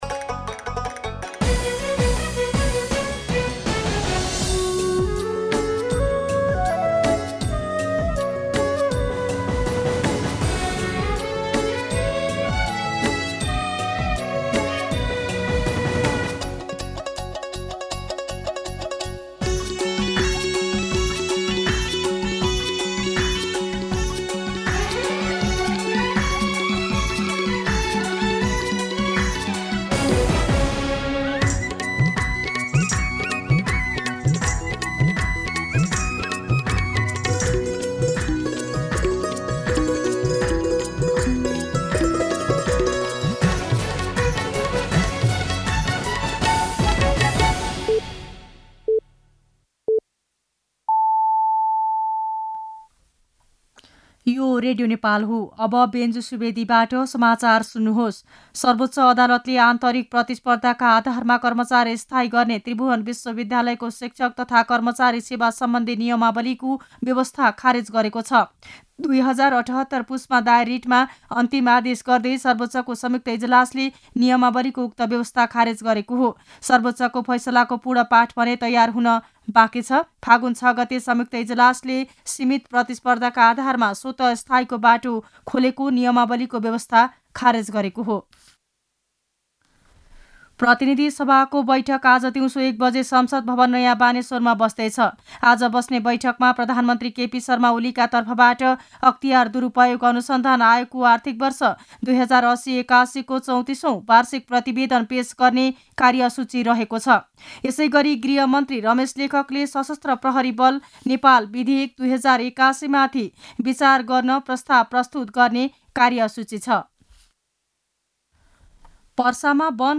मध्यान्ह १२ बजेको नेपाली समाचार : १३ फागुन , २०८१